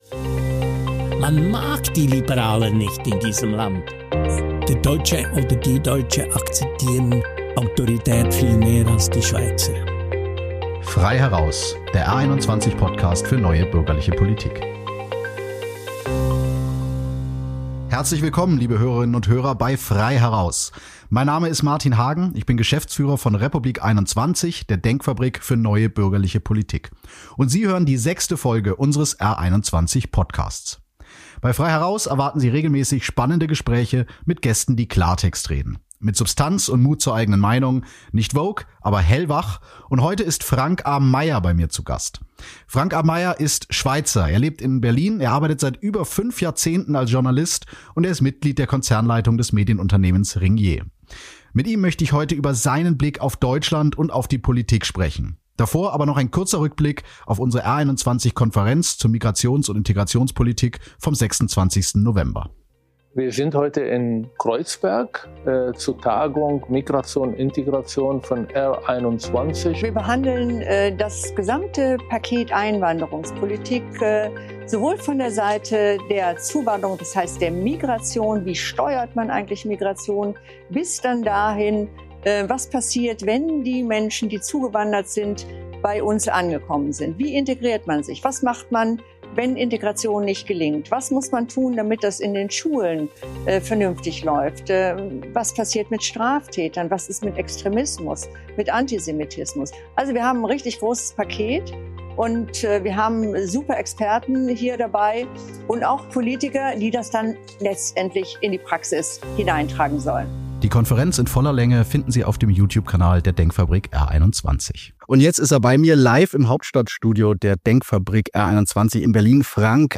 Der Schweizer Journalist spricht mit R21-Geschäftsführer Martin Hagen über seinen Blick auf Deutschland und die Politik.